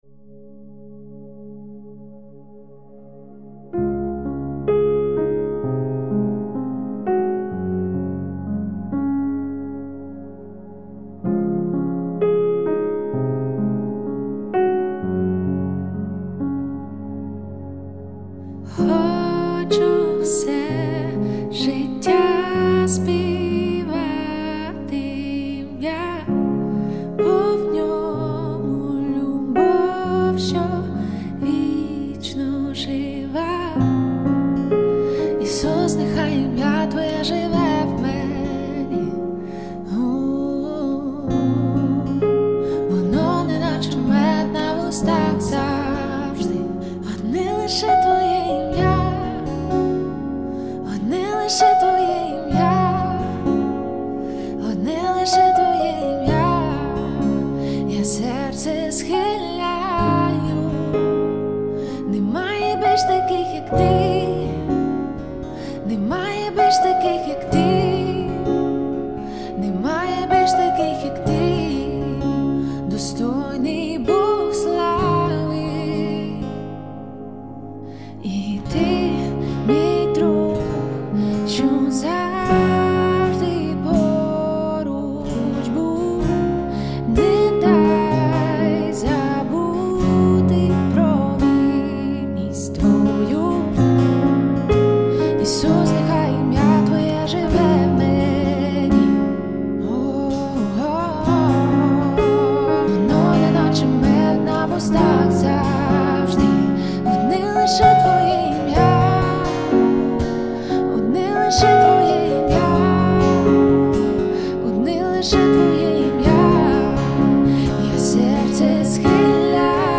4852 просмотра 3043 прослушивания 299 скачиваний BPM: 128